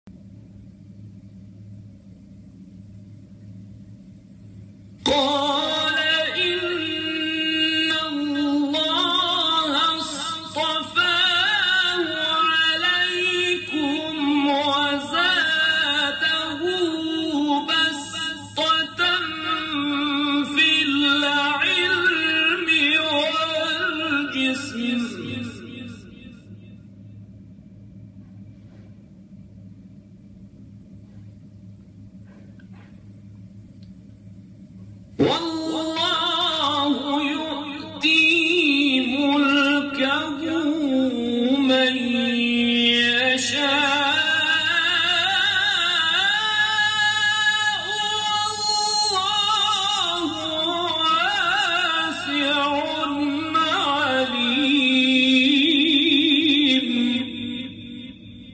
گروه شبکه اجتماعی: مقاطعی از تلاوت‌های صوتی قاریان برجسته کشور ارائه می‌شود.